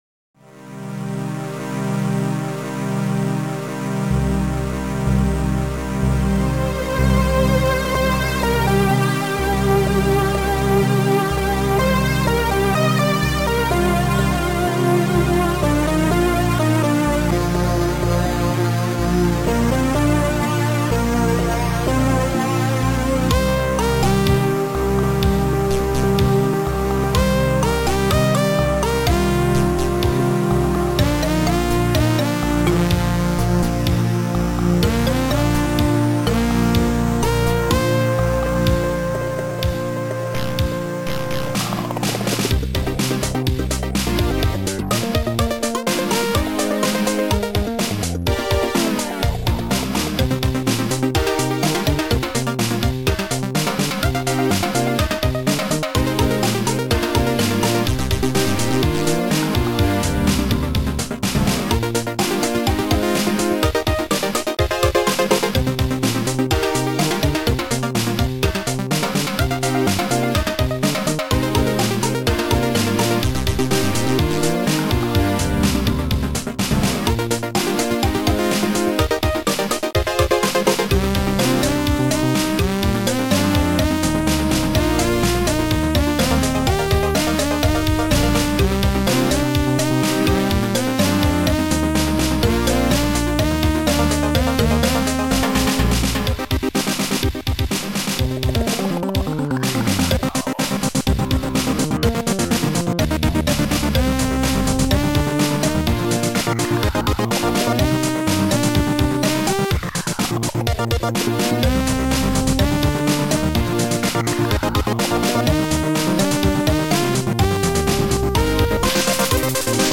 Sound Format: Noisetracker/Protracker
Sound Style: Mellow